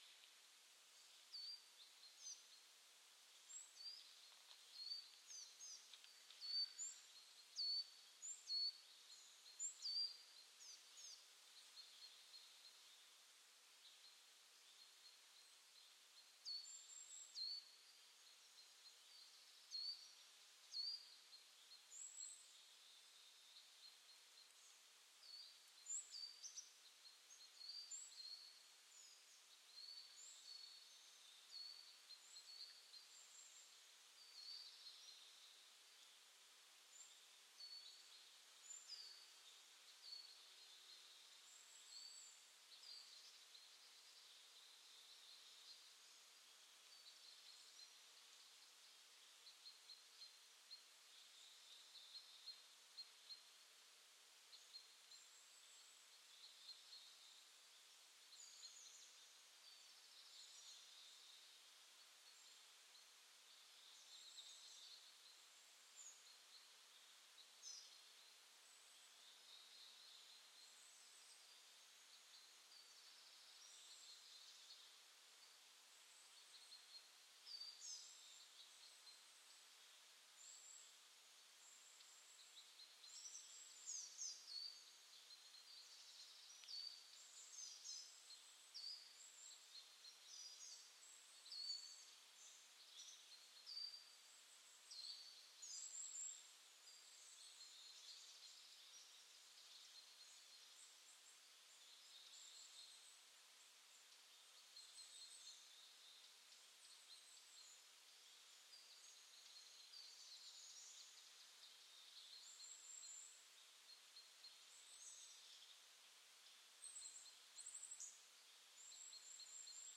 描述：森林 晚上
Tag: 场记录 森林 鸟类 性质 氛围